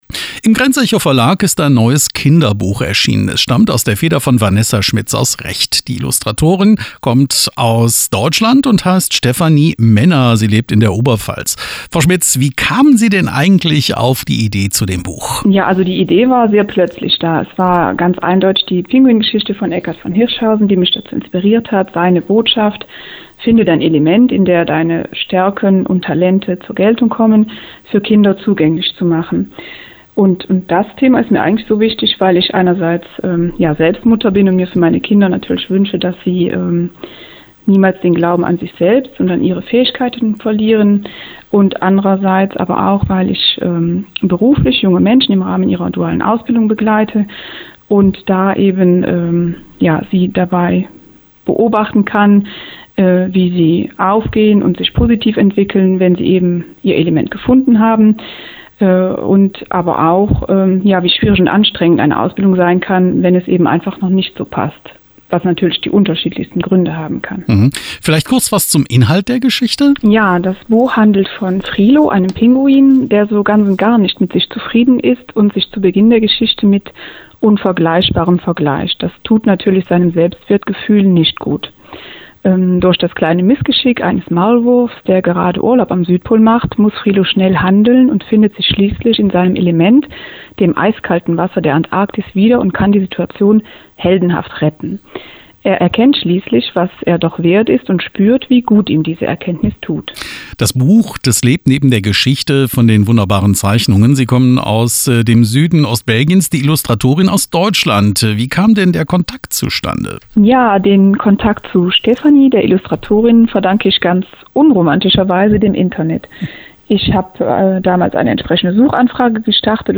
Frilo-Interview.mp3